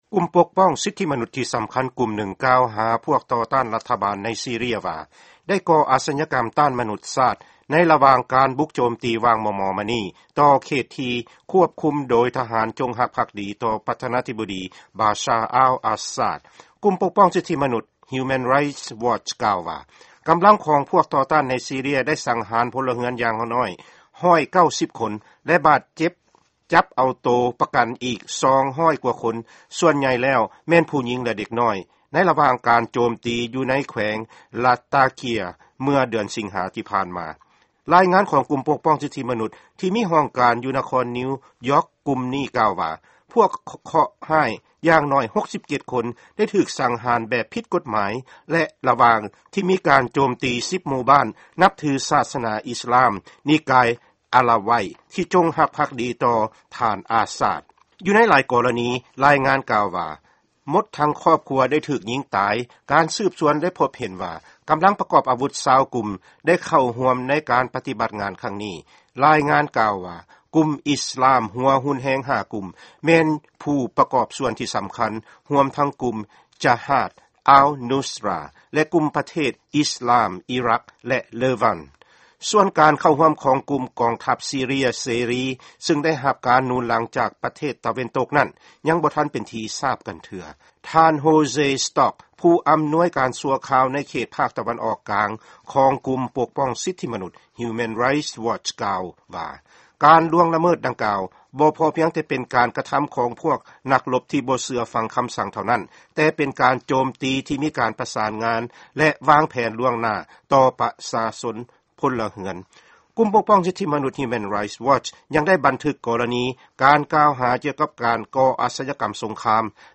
ຟັງຂ່າວ ປະເທດຊີເຣຍ